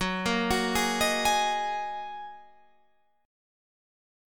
F#6add9 chord